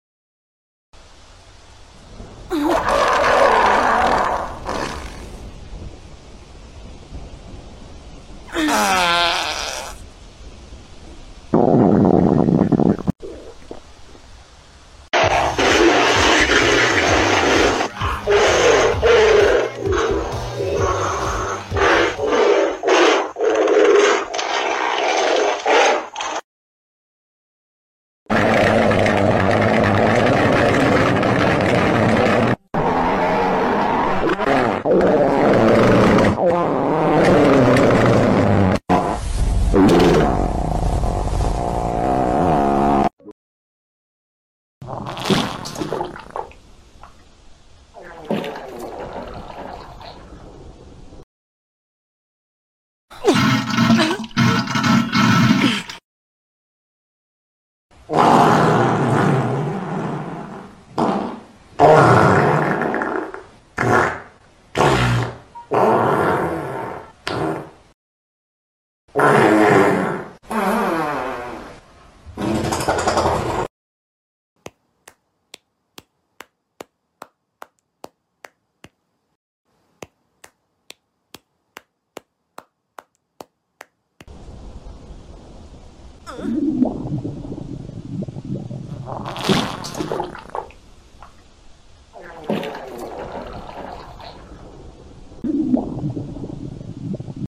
Sprunki clukr poop fart diarrhea sound effects free download
Sprunki clukr poop fart diarrhea on diaper